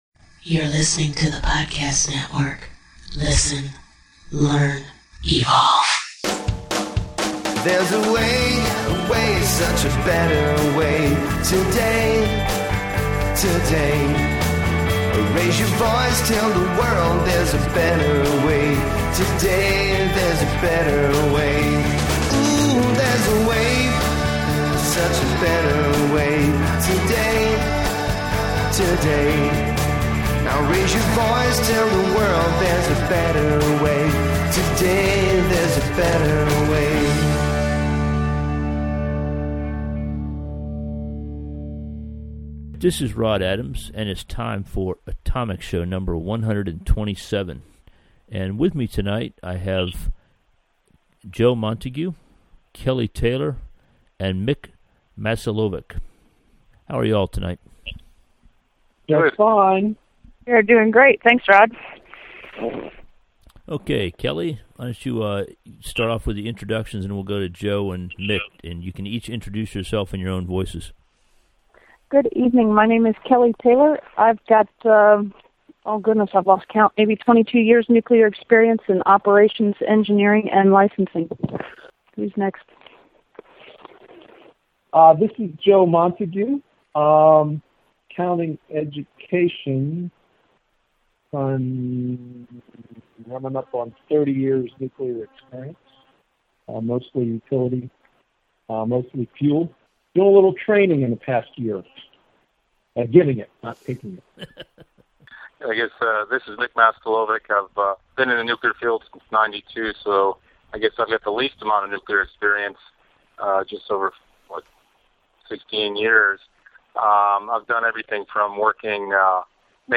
On February 3, 2009, I attended a public meeting regarding the future construction of North Anna Unit 3. Several of the people who attended that meeting gathered via Skype last night to share notes.